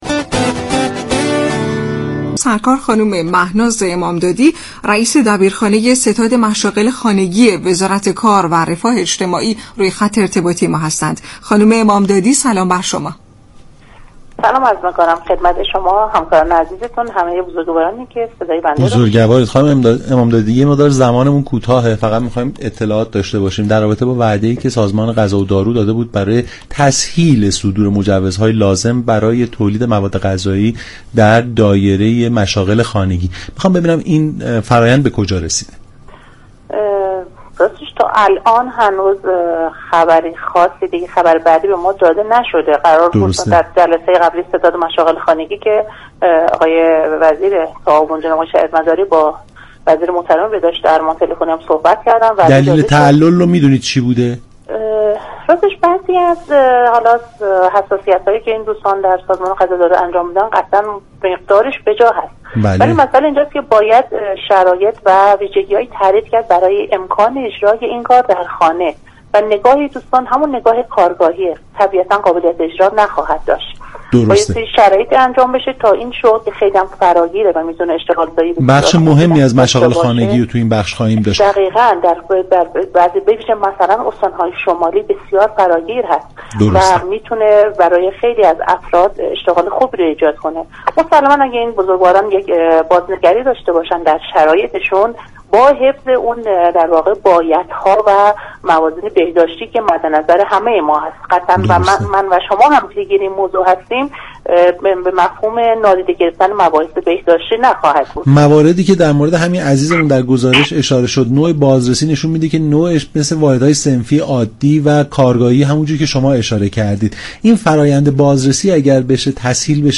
در گفت و گو با برنامه «نمودار»